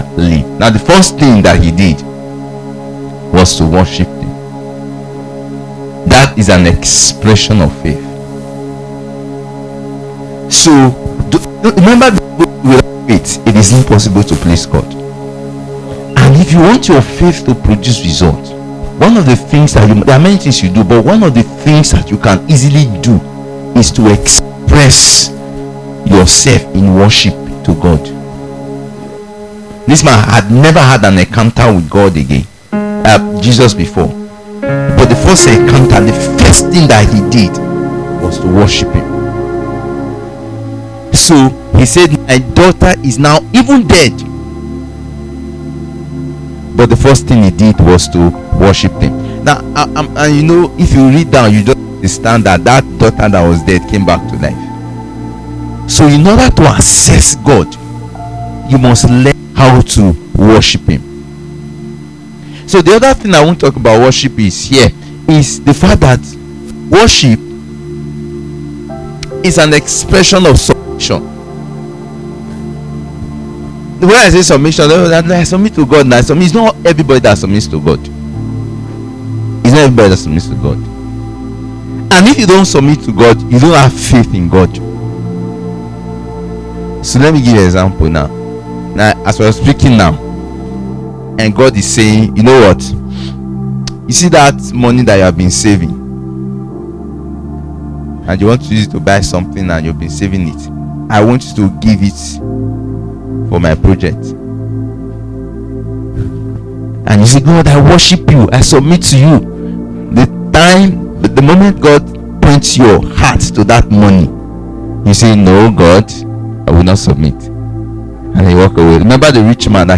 Purpose Service Type: Revelation Service Worship is everything between God and Man.